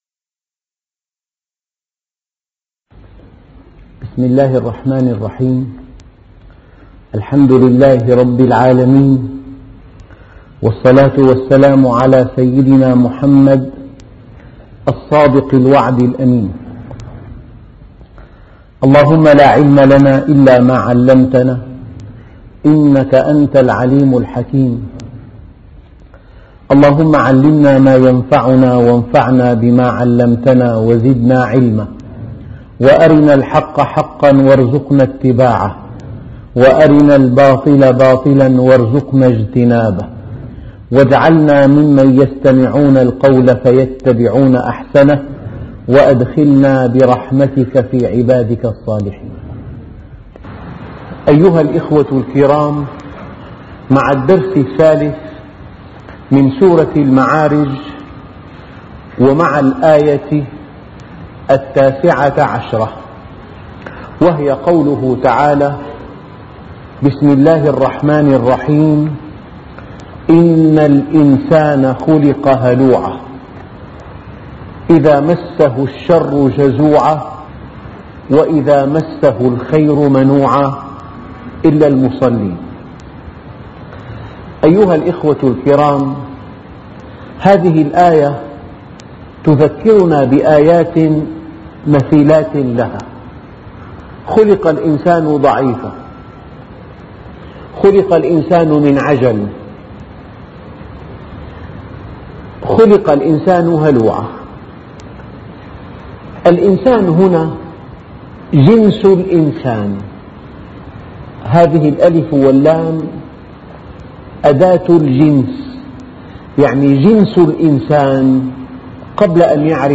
أرشيف الإسلام - ~ أرشيف صوتي لدروس وخطب ومحاضرات د. محمد راتب النابلسي